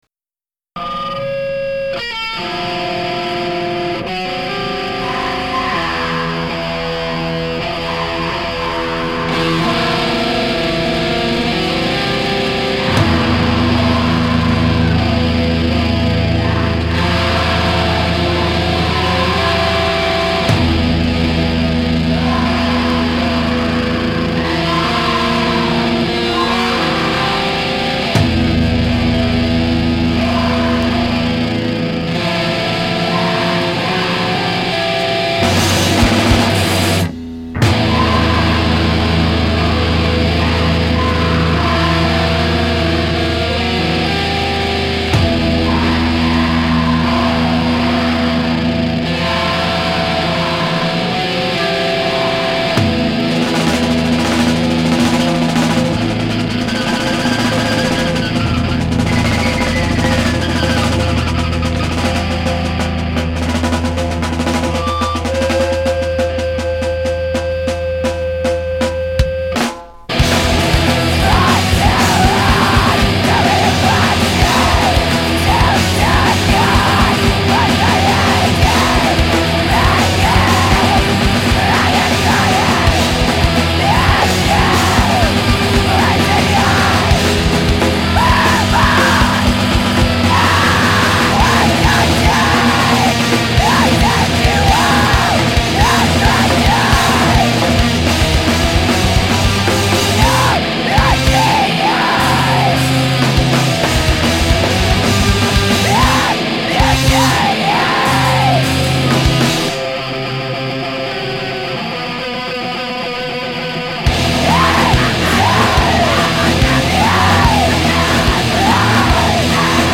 Скримо